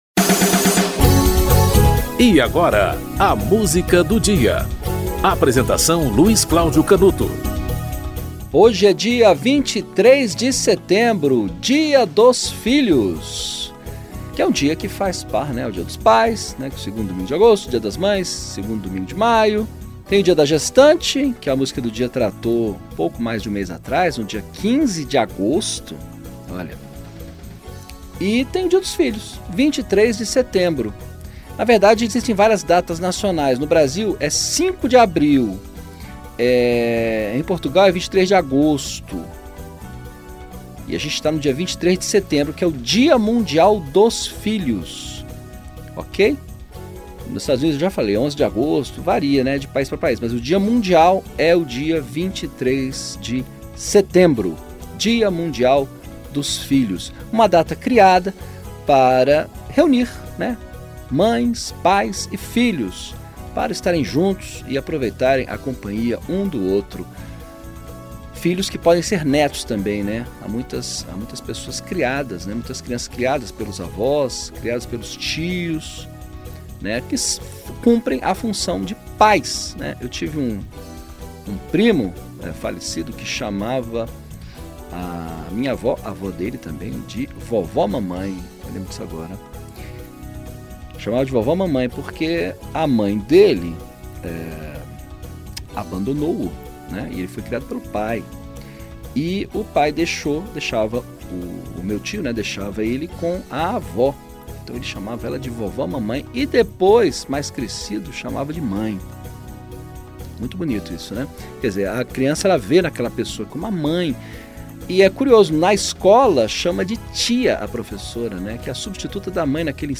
Elomar - Balada do Filho Pródigo (Elomar)